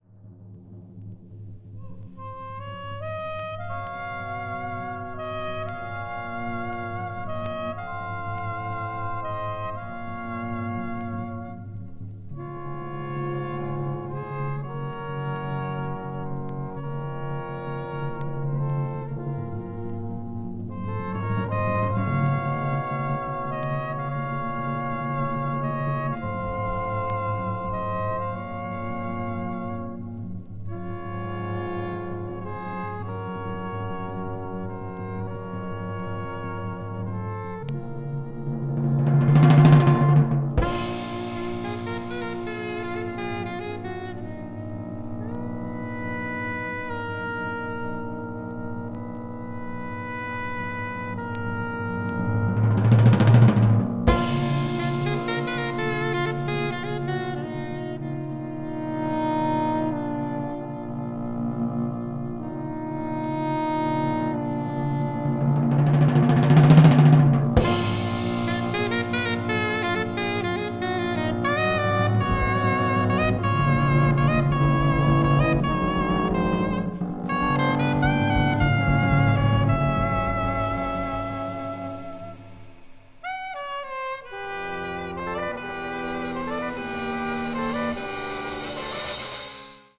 media : EX/EX(わずかにチリノイズが入る箇所あり)
avant-jazz   contemporary jazz   free jazz